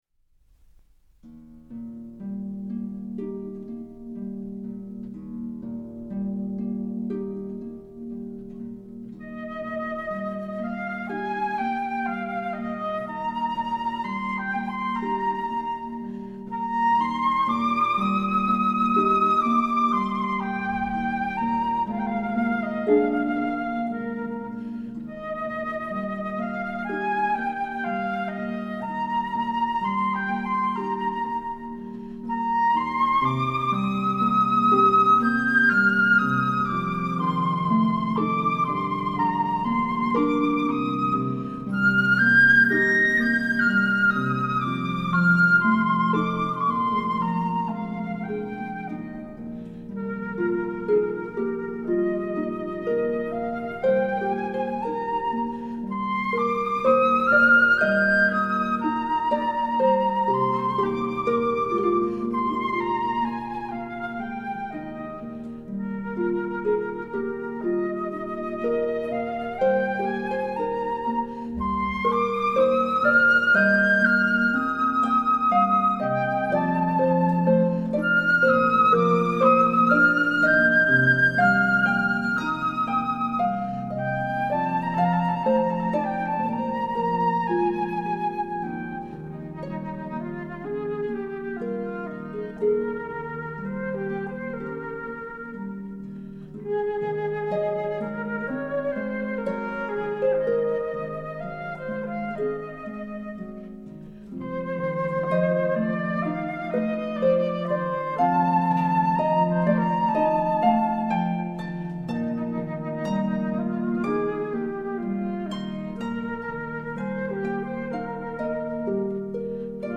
小品式的音乐，长笛、钢琴和竖琴的组合
录音的音色也是赏心悦耳
它的音色柔美，金属光泽中透出一种人性化的特质
这三件乐器的录音效果则非比寻常，声部的平衡感和音质的透彻感绝可媲美世界同类制作。